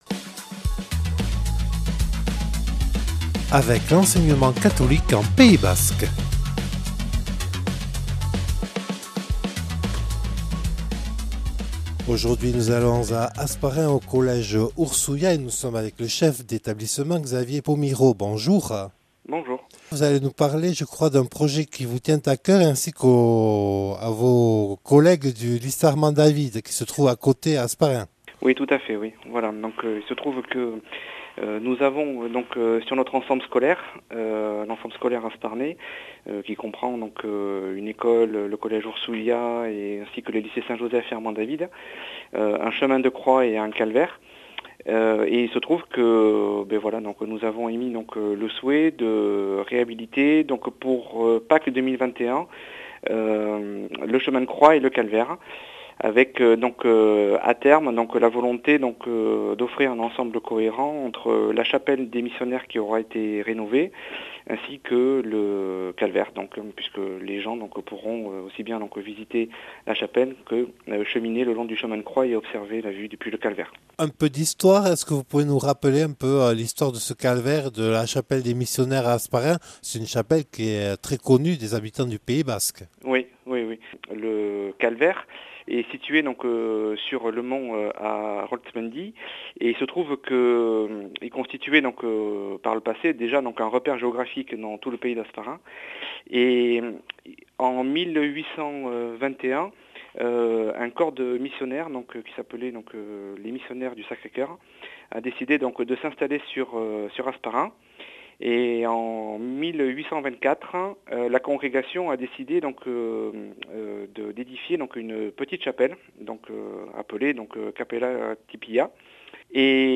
Une interview